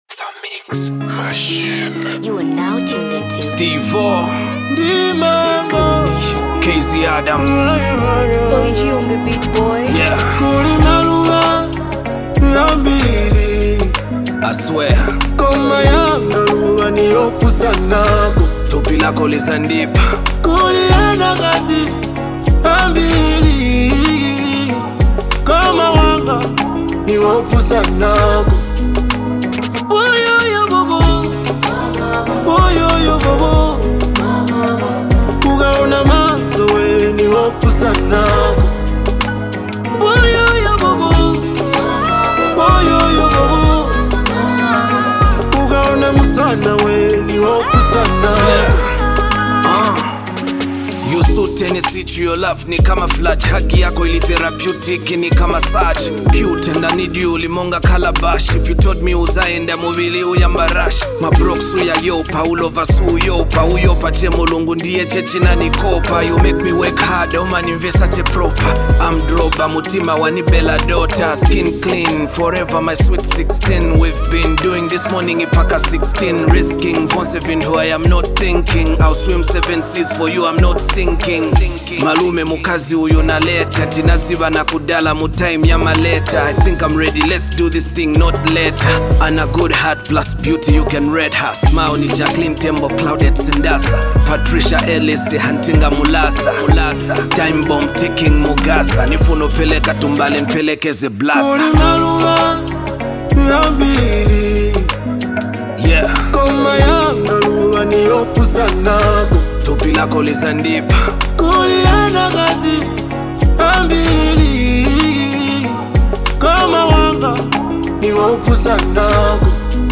With a sharp, cutting beat and direct lyrics